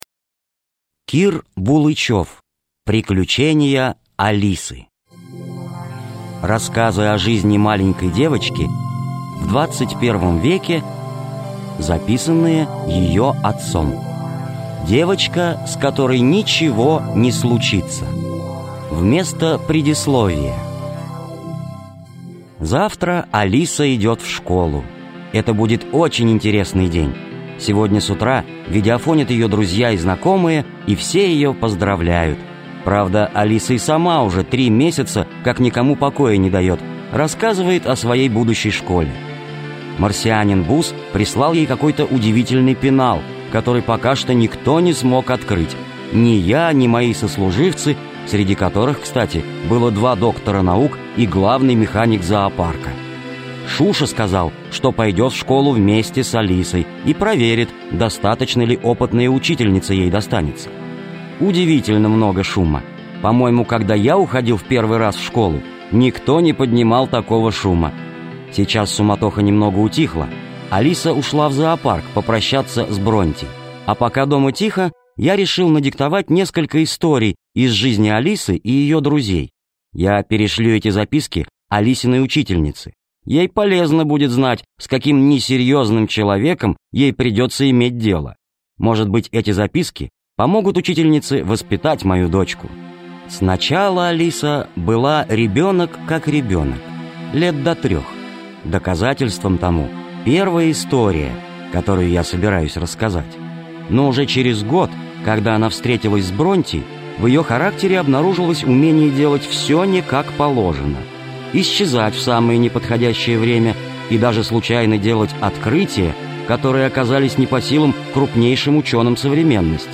Девочка, с которой ничего не случится - аудио рассказы Булычева К. Фантастические рассказы про Алису, дочку профессора Селезнева.